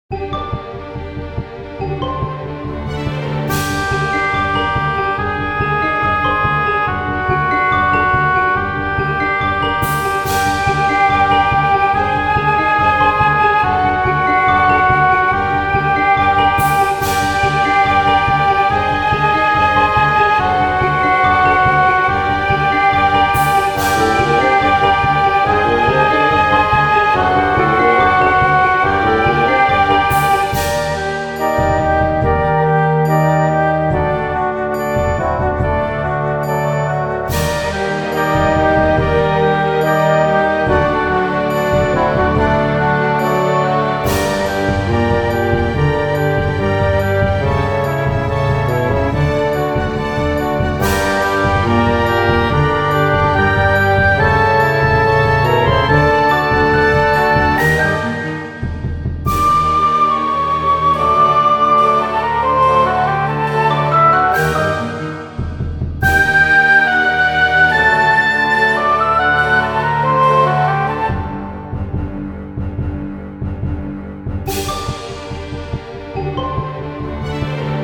緊迫